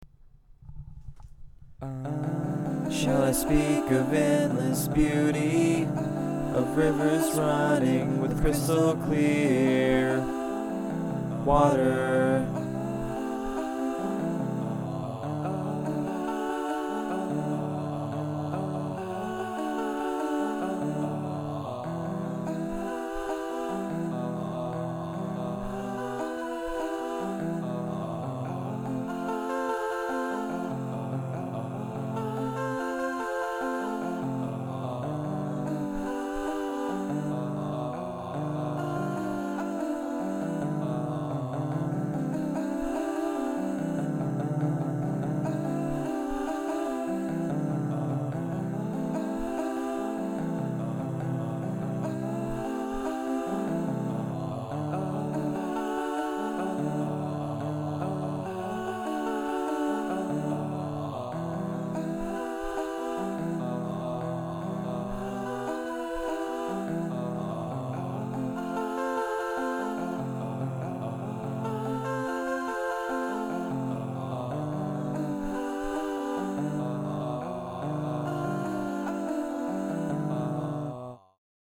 I recorded myself singing short little "Ah's" at different pitches and rearranged them into different patterns.
I then sang a quick lead over this.
My wife described the background vocals as sounding like distinct drops of water which went well with the imagery painted in the vocals.
The demo up above is essentially in a 4/4 pattern, but the final song is in 3/4.